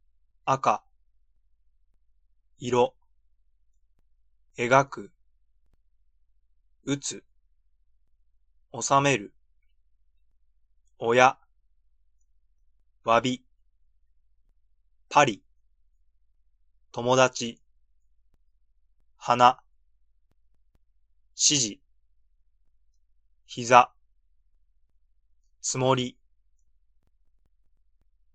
Ja-LessonIntroKonPron-practice1.mp3